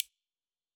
UI Sounds
Minimalist1.wav